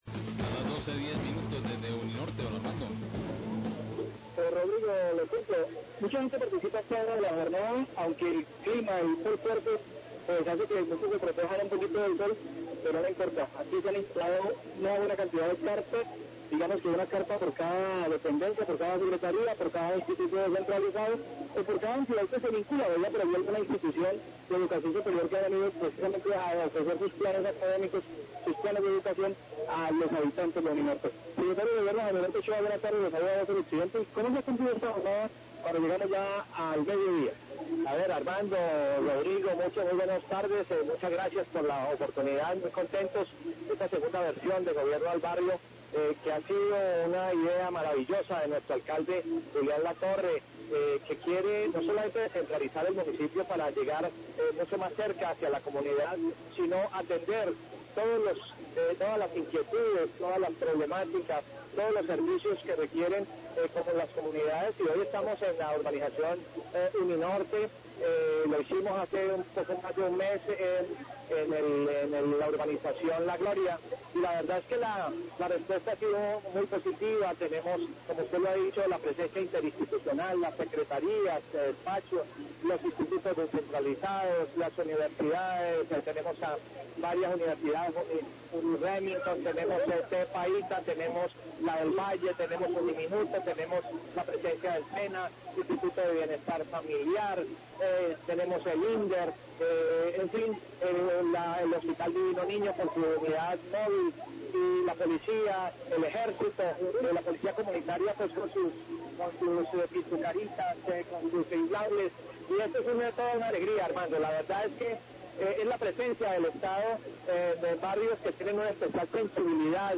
Radio
El secretario de Gobierno, Jaime Alberto Ochoa, brinda un balance de dicha jornada